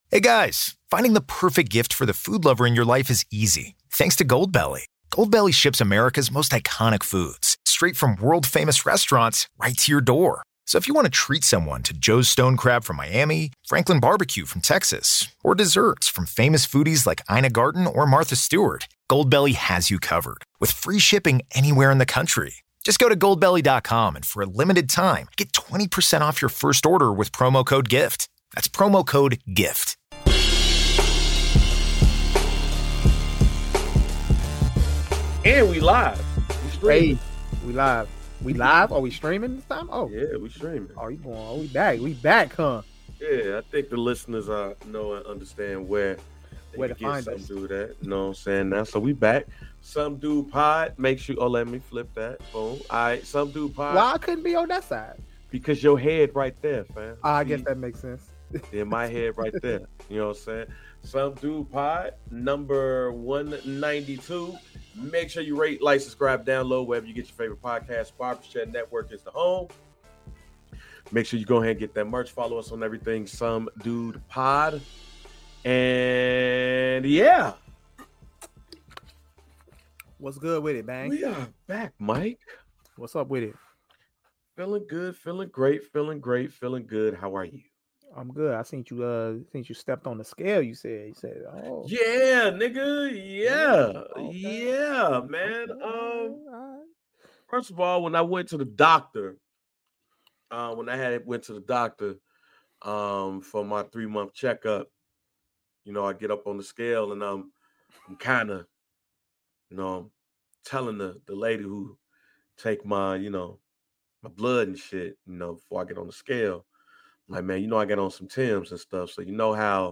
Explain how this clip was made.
We are finally back streaming live!